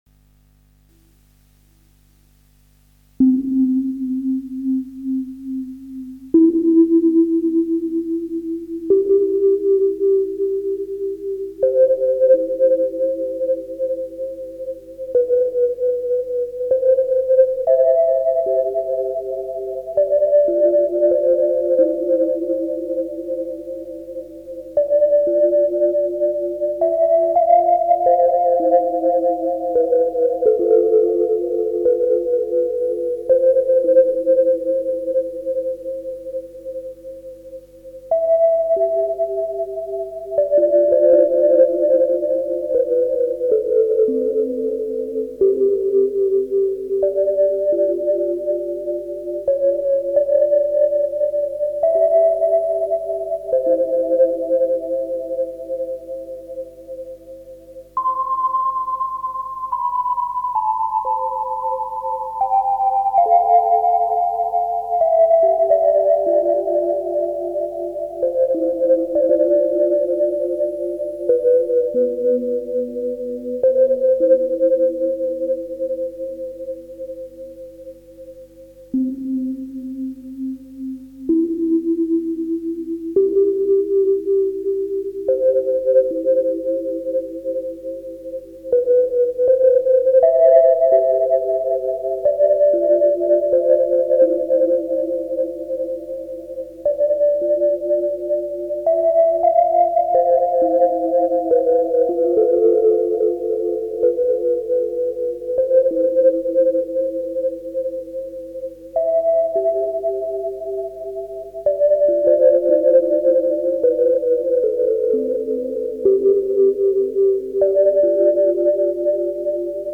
Flauto di Pan a aa strumenti musicali
MUSICA CLASSICA